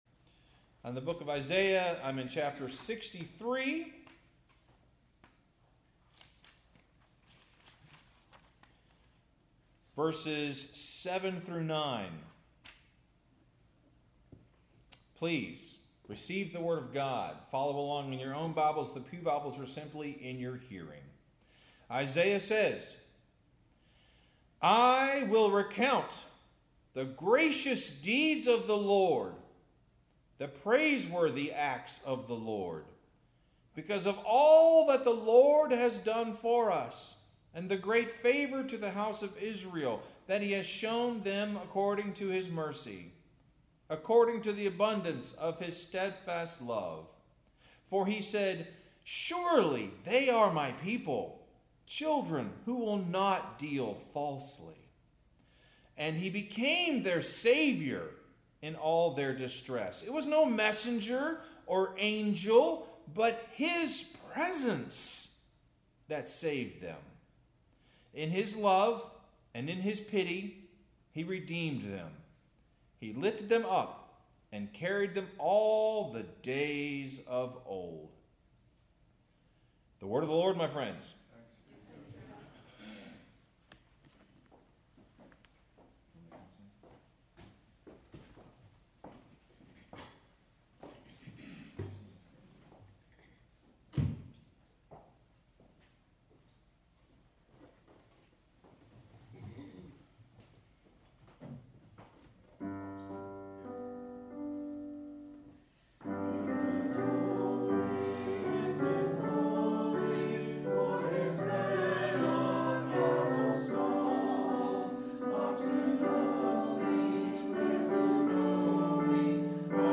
Sermon – Where’s Jesus?